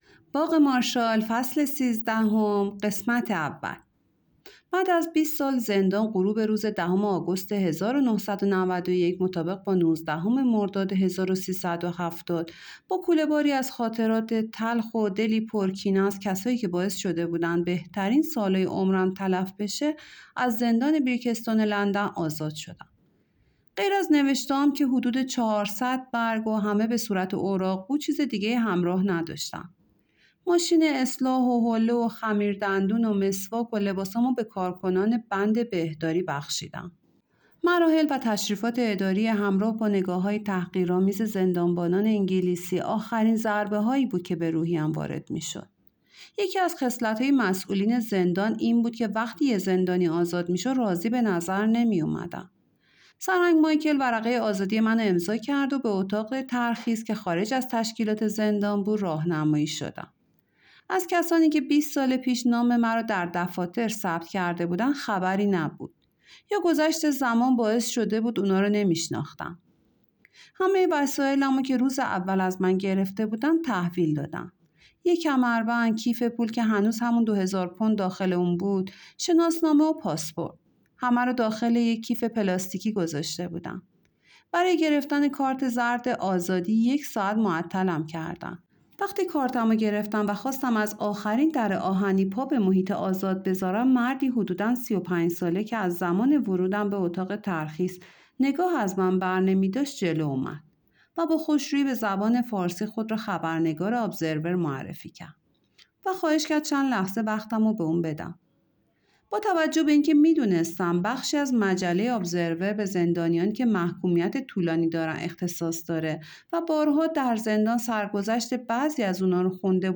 رمان صوتی ایرانی Podcast - باغ مارشال فصل سیزدهم قسمت اول | Free Listening on Podbean App